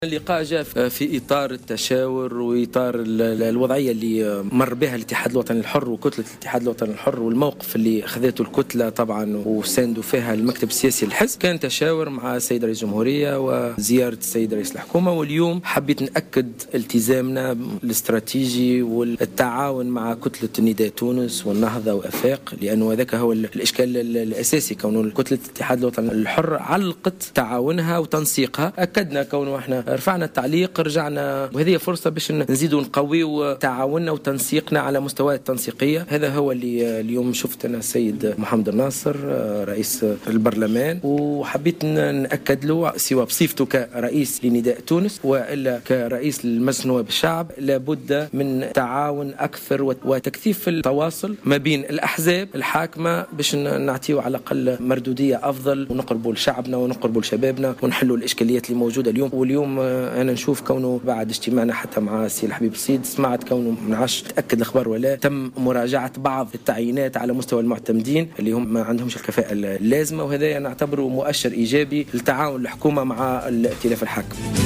وقال الرياحي في تصريح لمراسل "الجوهرة أف أم" إنه أكد خلال هذا اللقاء التزام كتلة حزبه البرلمانية بالتعاون مع كتل أحزاب الائتلاف الحاكم.